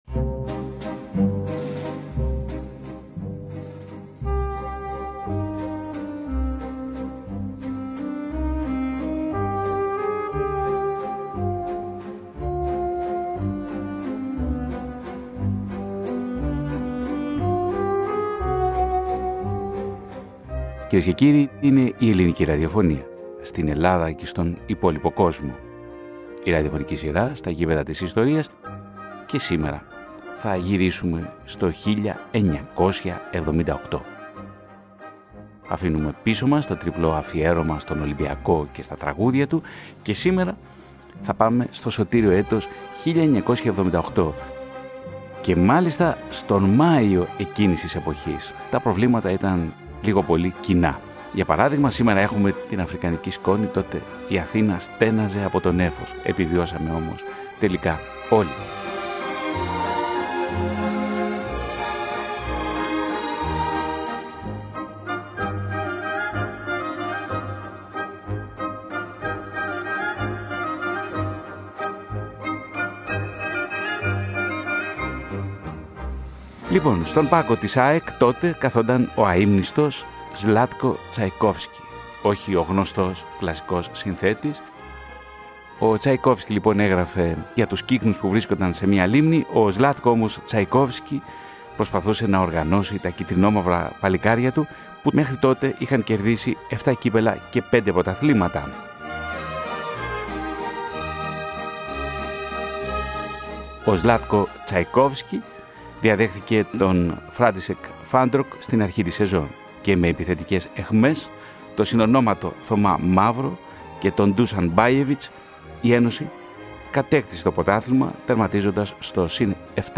Η ραδιοφωνική σειρά στα “Γήπεδα της ιστορίας” πραγματοποιεί ένα ραδιοφωνικό ντοκιμαντέρ για την ΑΕΚ του 1978 και μαζί ένα ταξίδι στις χωμάτινες αλάνες, στους Boney M και στην αθωότητα εκείνης της εποχής, τέσσερα μόλις χρόνια μετά τη Μεταπολίτευση.